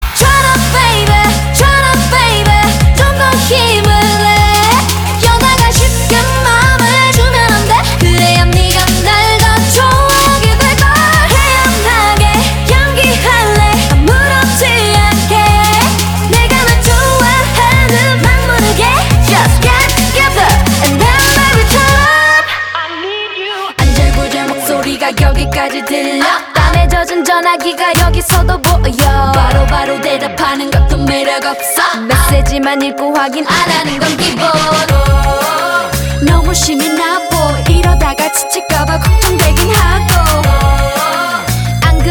веселые
заводные
K-Pop
качает
Крутая корейская песня